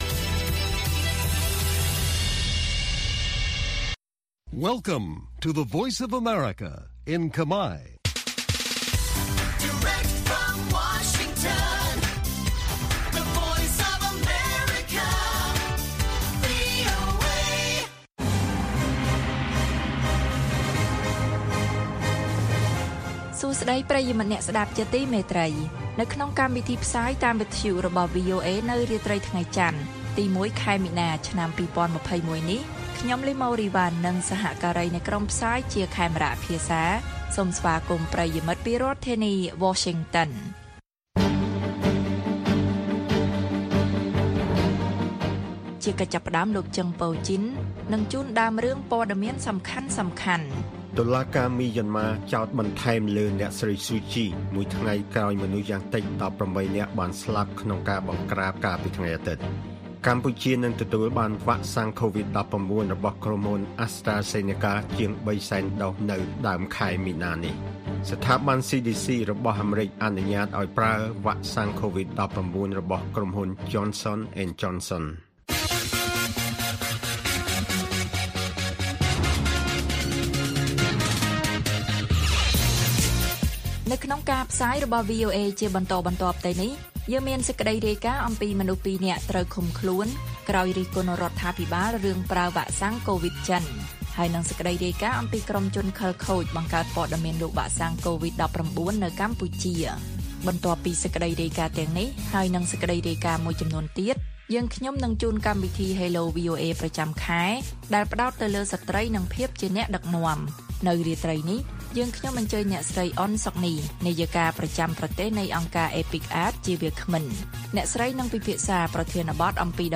ព័ត៌មានពេលរាត្រី៖ ១ មីនា ២០២១